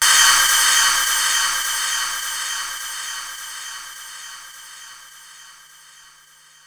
Index of /kb6/Korg_05R-W/Korg Cymbals
Cym SplashLoop X5.wav